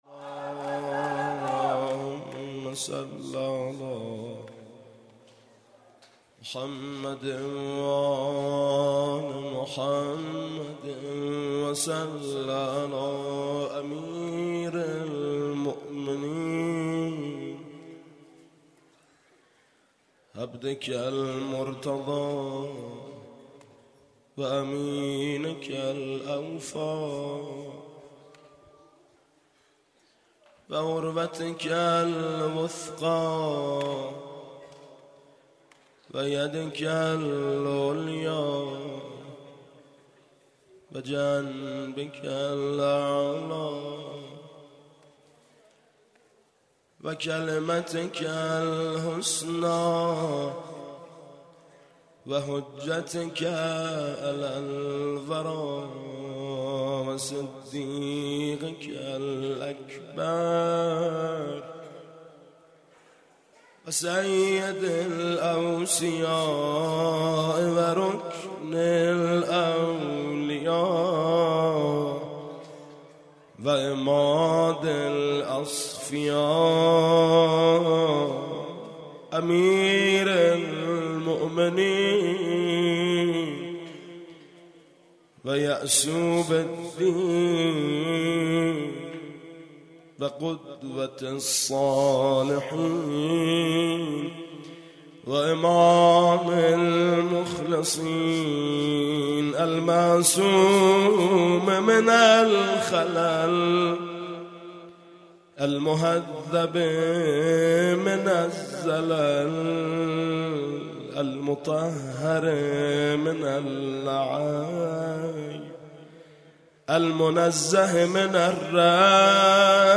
صوت صلوات بر امیرالمؤمنین علی (علیه السلام) با صدای میثم مطیعی.